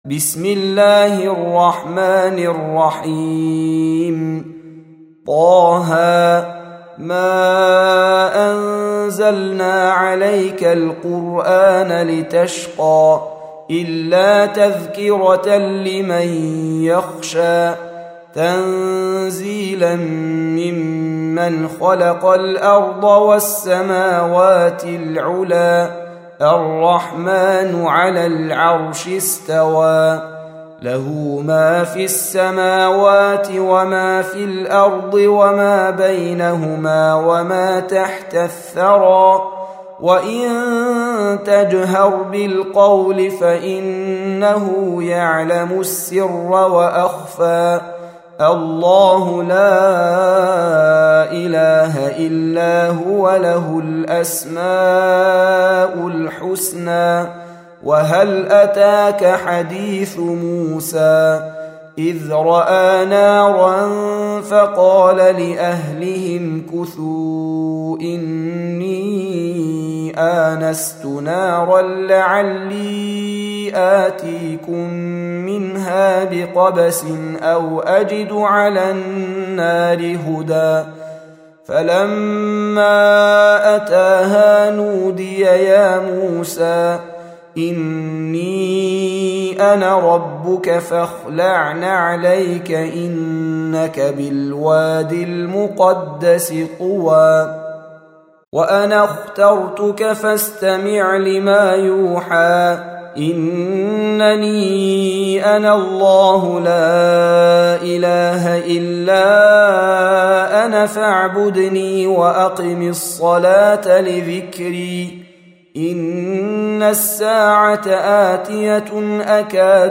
Surah Repeating تكرار السورة Download Surah حمّل السورة Reciting Murattalah Audio for 20. Surah T�H�. سورة طه N.B *Surah Includes Al-Basmalah Reciters Sequents تتابع التلاوات Reciters Repeats تكرار التلاوات